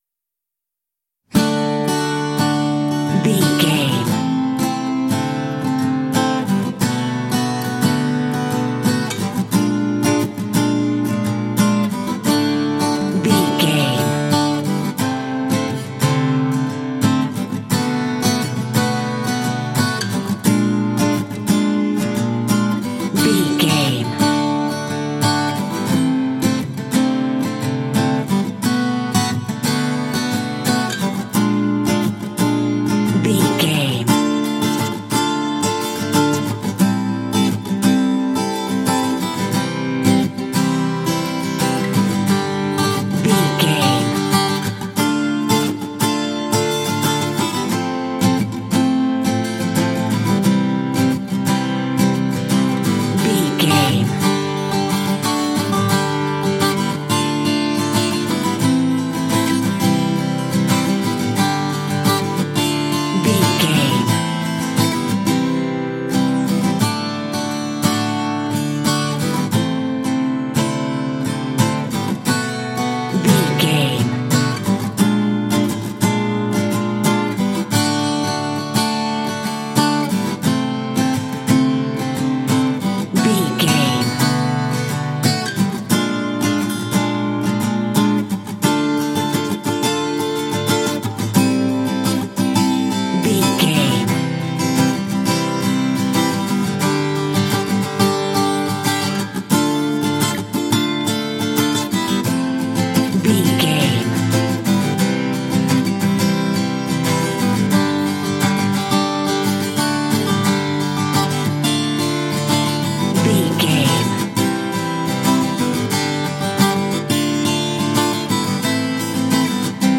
Ionian/Major
light
dreamy
sweet
orchestra
horns
strings
percussion
cello
cinematic
pop